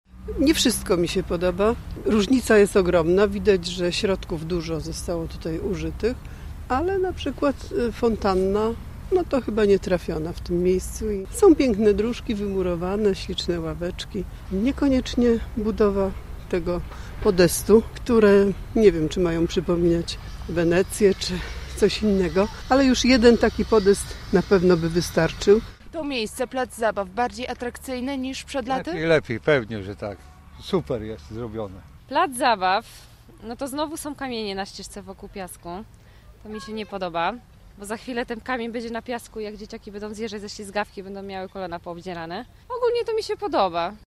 Mieszkańcy, których spotkaliśmy w parku są zgodni, że efekty renowacji są mocno widoczne, choć nie wszystkie zmiany im się podobają.